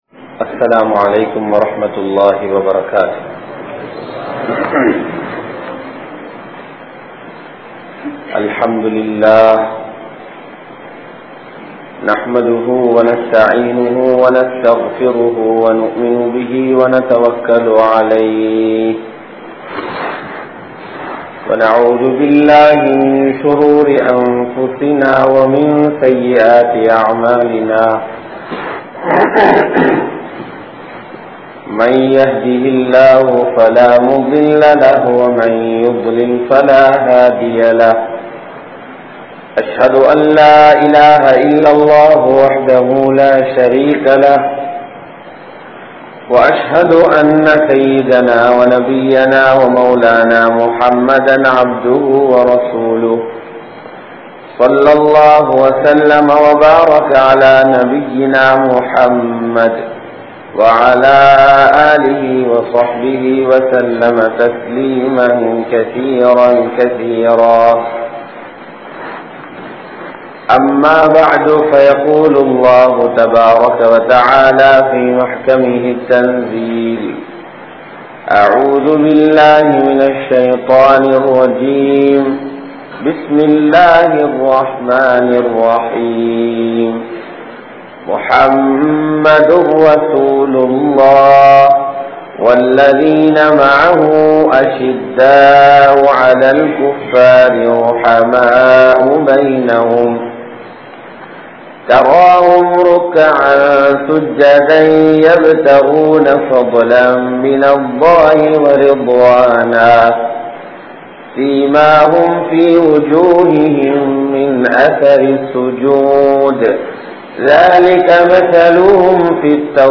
Manakibus Shahaba | Audio Bayans | All Ceylon Muslim Youth Community | Addalaichenai